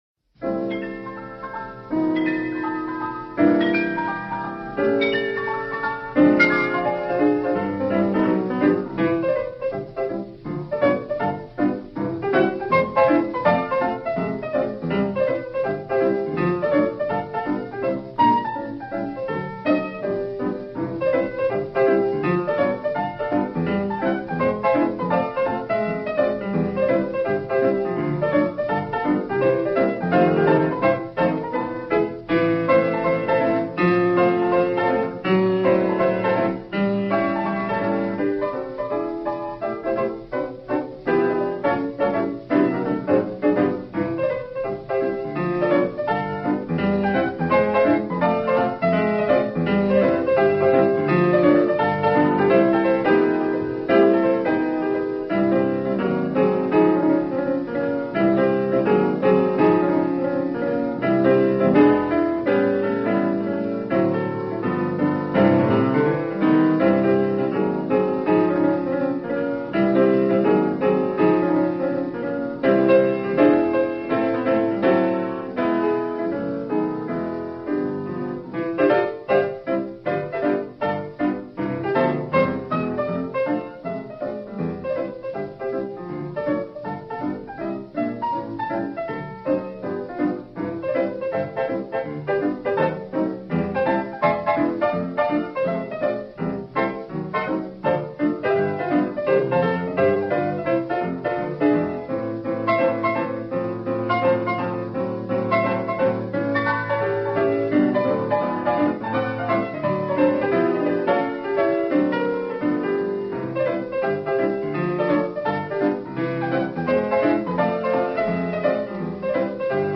The master of the stride piano